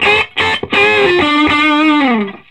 Index of /90_sSampleCDs/USB Soundscan vol.22 - Vintage Blues Guitar [AKAI] 1CD/Partition C/04-SOLO D125